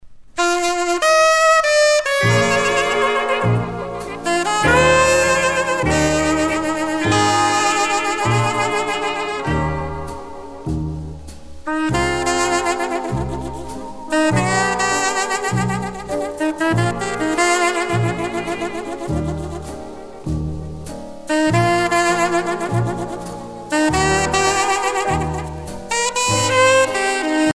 danse : slow
Pièce musicale éditée